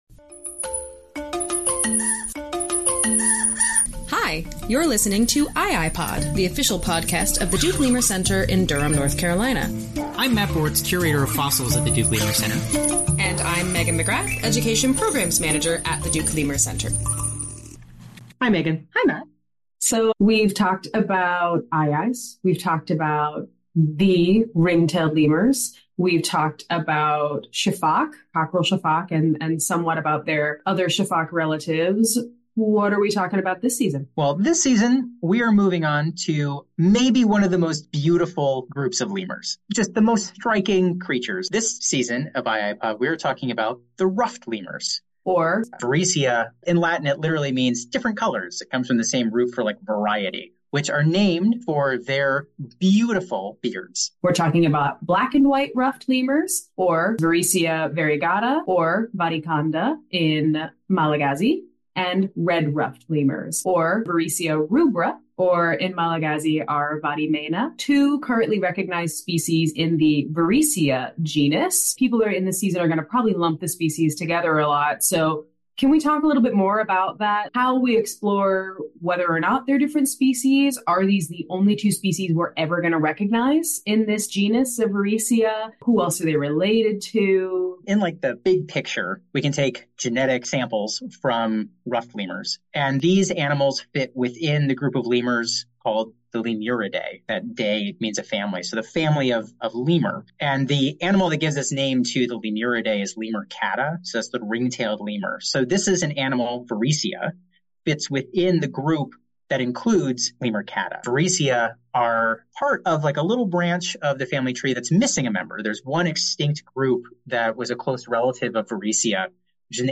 In this episode, we discuss the basics of the two species in the Varecia genus – black and white ruffed lemurs and red ruffed lemurs. This is our longest season yet, full of fascinating interviews with ruffed lemur caretakers,